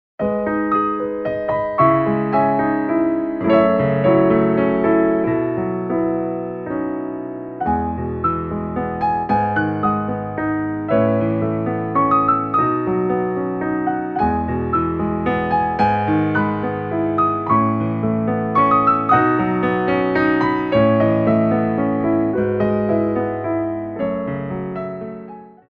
Développés
3/4 (8x8)